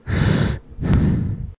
gas_mask_middle_breath1.wav